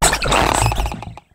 veluza_ambient.ogg